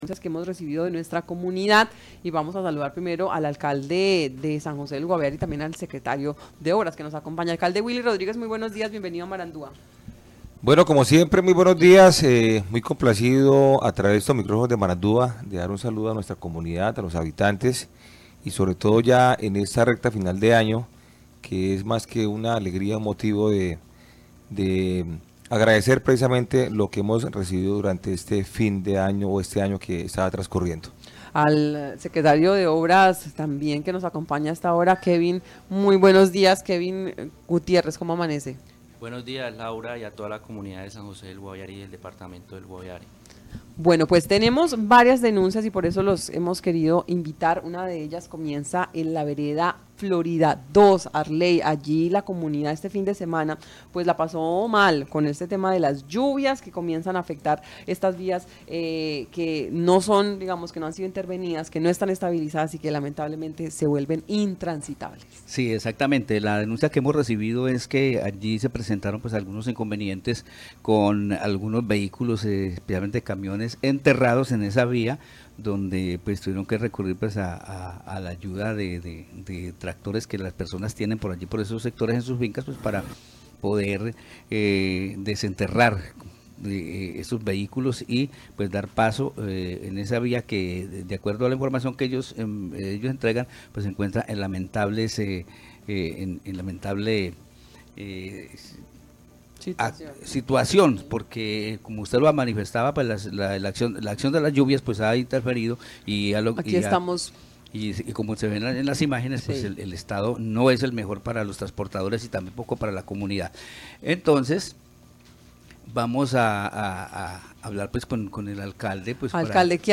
Alcalde de San José responde a quejas por el mal estado de vías rurales